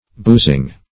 boozing \booz"ing\ n.